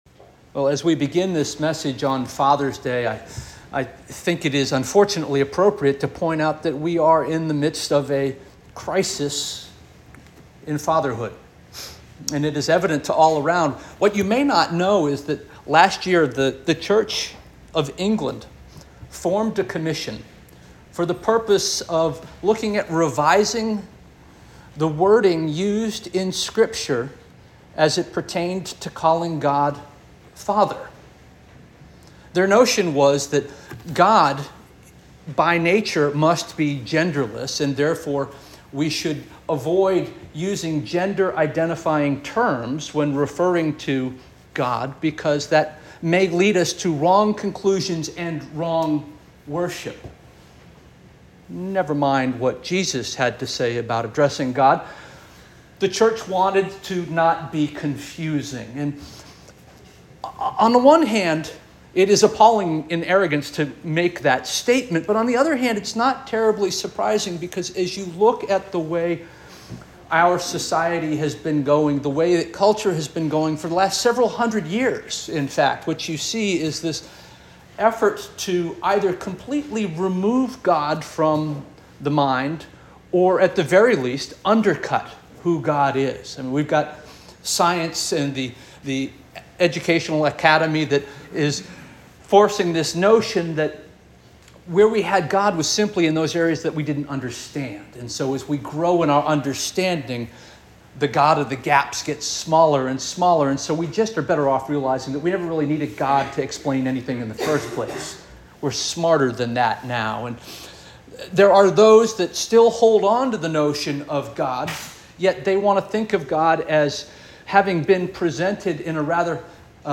June 16, 2024 Sermon - First Union African Baptist Church